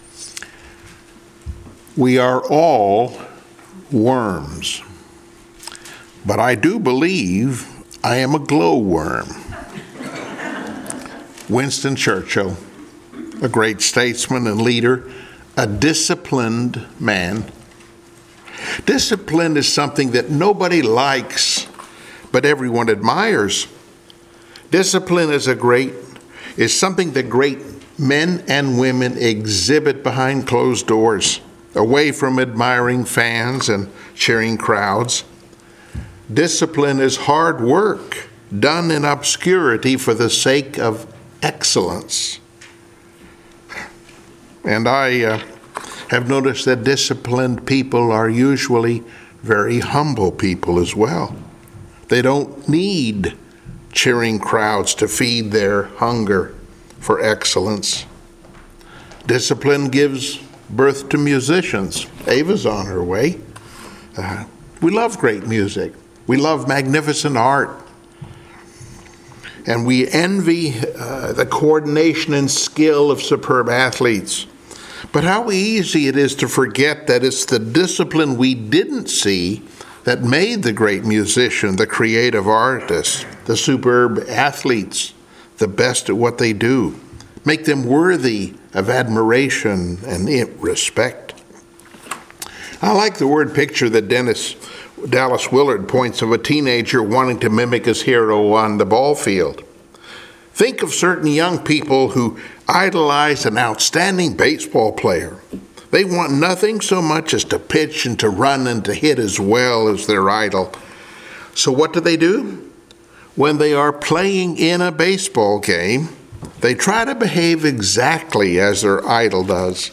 Passage: 1 Peter 5:5-7 Service Type: Sunday Morning Worship